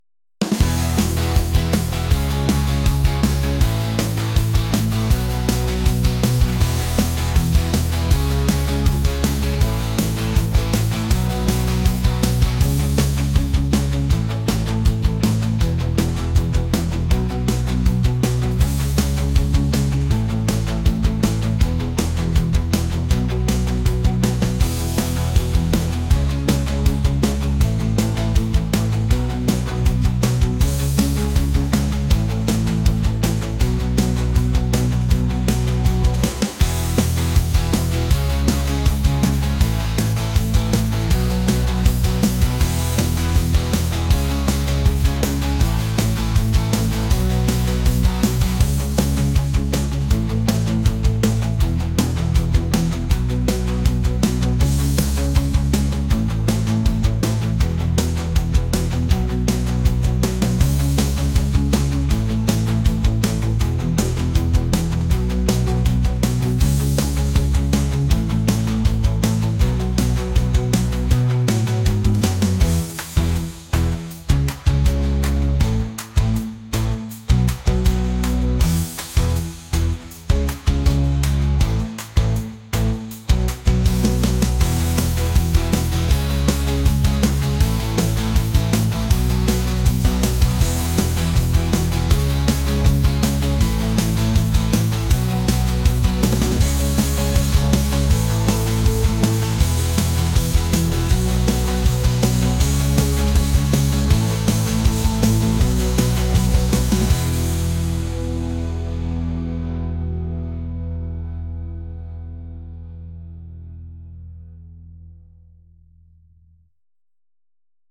pop | acoustic | electronic